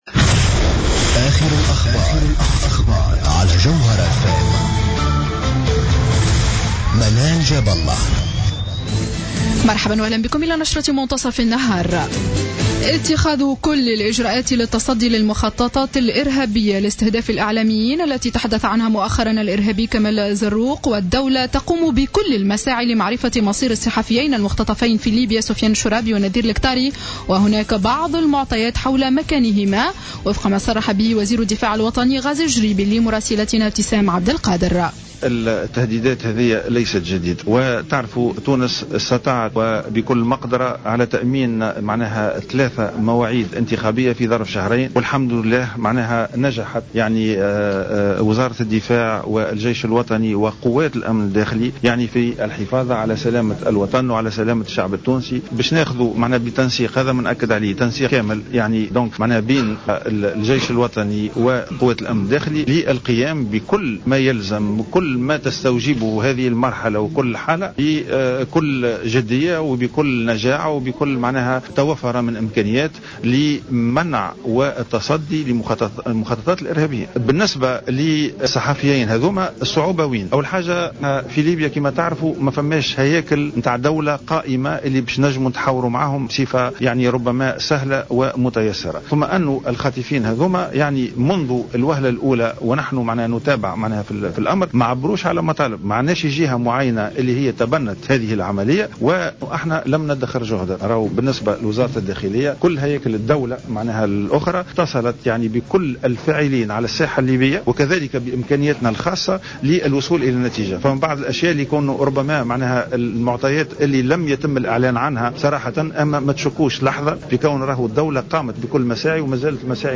نشرة أخبار منتصف النهار ليوم الاثنين 12-01-15